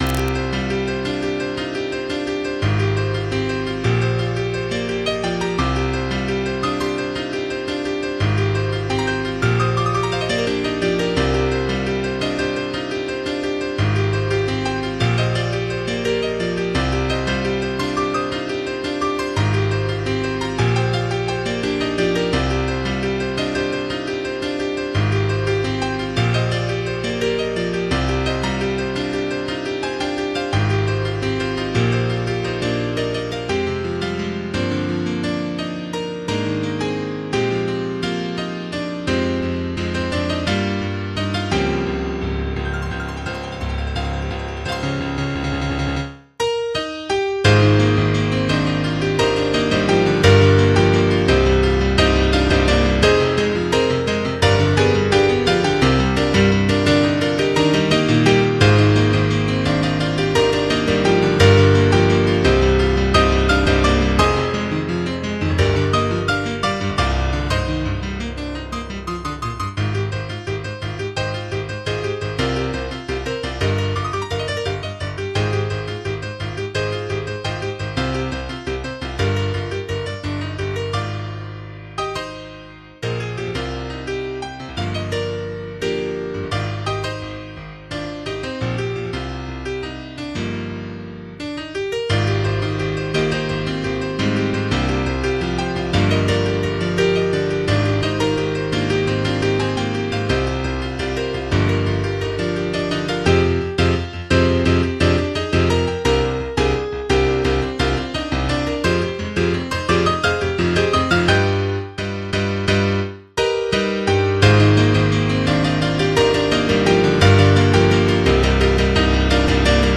MIDI 17.55 KB MP3 (Converted)